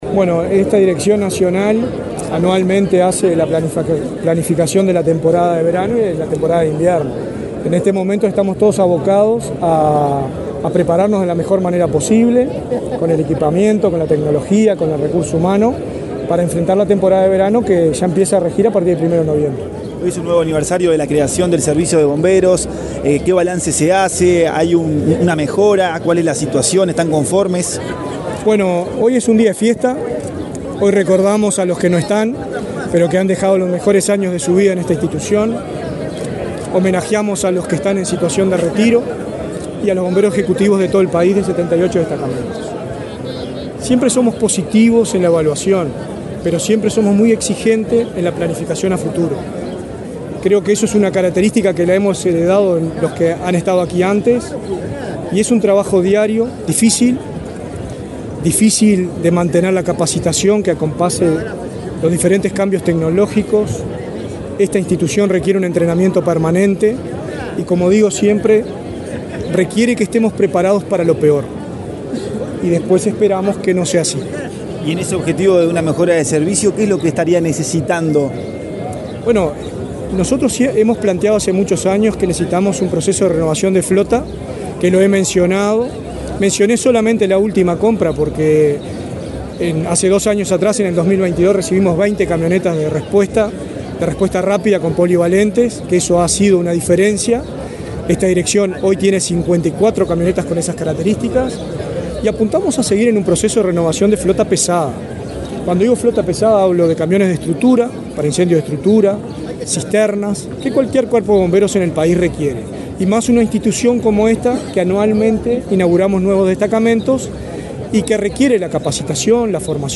Declaraciones del director nacional de Bomberos, Richard Barboza
El titular de la Dirección Nacional de Bomberos, Richard Barboza, dialogó con la prensa, luego de participar en la ceremonia conmemorativa del 137.°